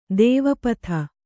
♪ dēva patha